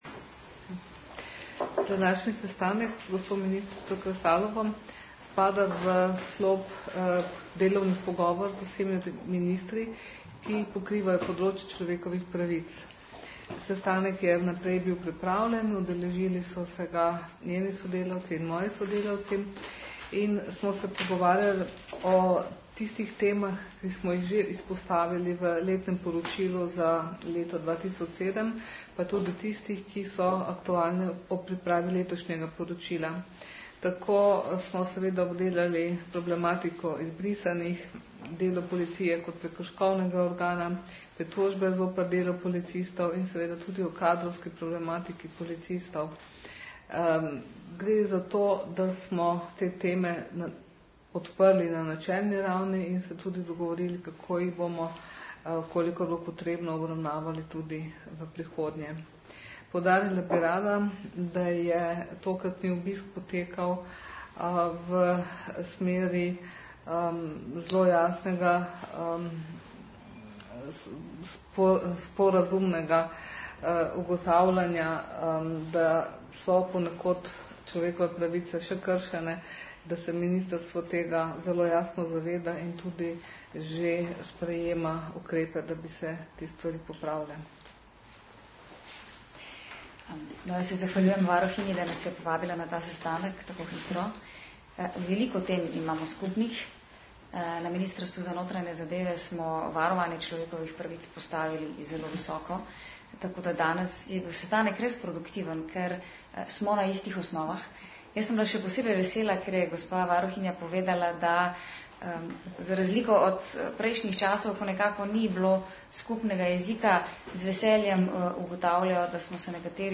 Zvočni posnetek izjave po srečanju ministrice in varuhinje